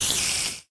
sounds / mob / spider / say1.ogg